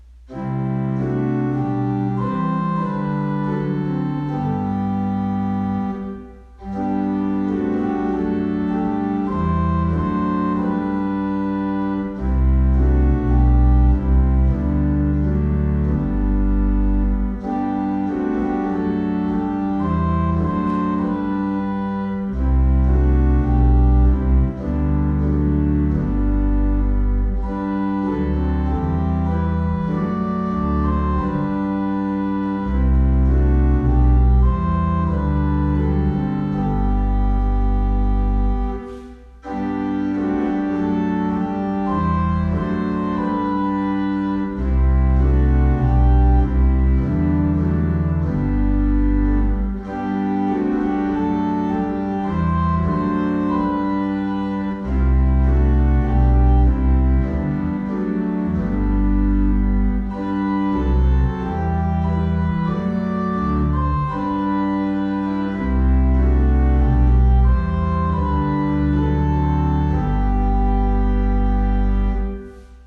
OPENING HYMN